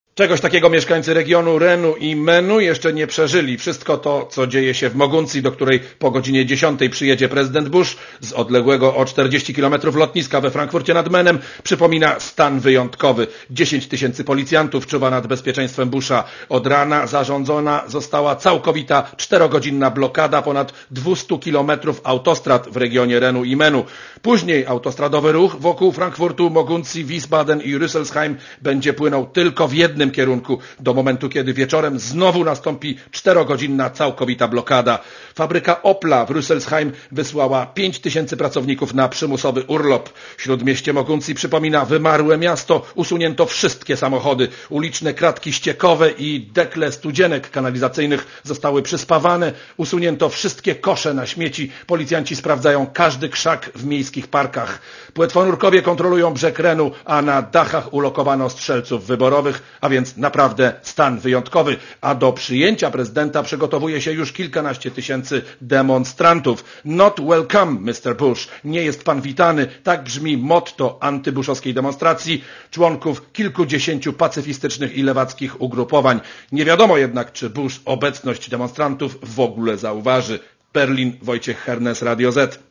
Korespondencja z Niemiec